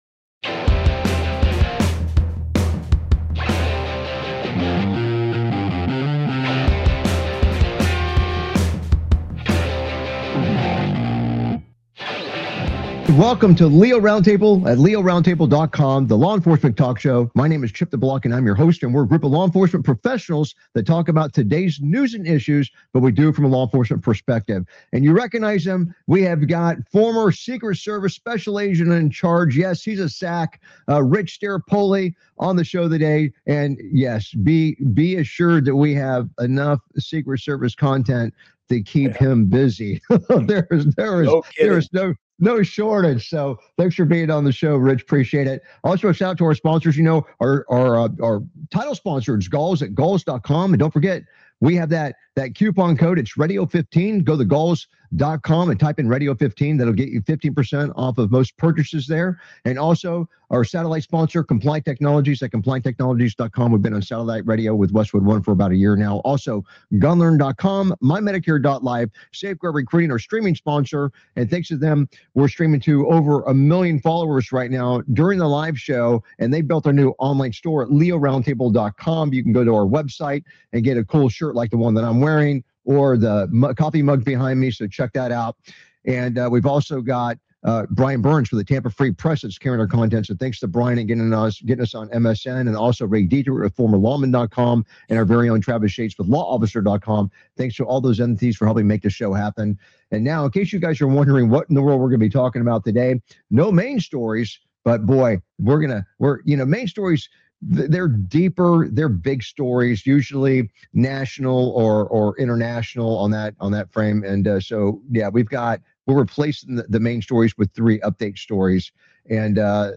Talk Show Episode, Audio Podcast, LEO Round Table and S11E065, Bad Guy Nearly Hits Officer With Motorcycle After A High Speed Escape! on , show guests , about Secret Service agent assigned to Dr,Jill Biden shot himself in the leg,SS agent suspended for Butler situation suspended again,Leak investigation opened into Joe Kent,Suspect shot by cop after raising firearm,Bad guy nearly hits officer with motorcycle after a high speed escape,Secret Service,Negligent discharge,Security failures,FBI investigation, categorized as Entertainment,Military,News,Politics & Government,National,World,Society and Culture,Technology,Theory & Conspiracy